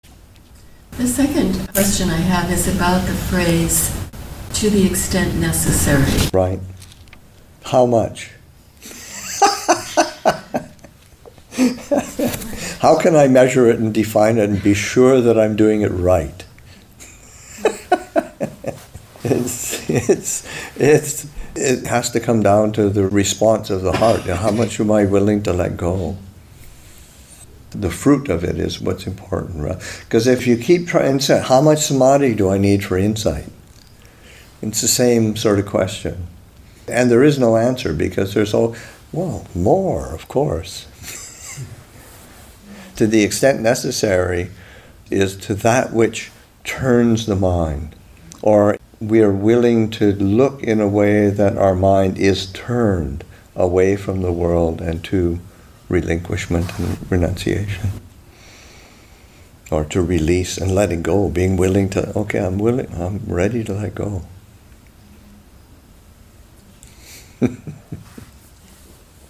Awaken to the New Year Retreat [2021], Session 3, Excerpt 1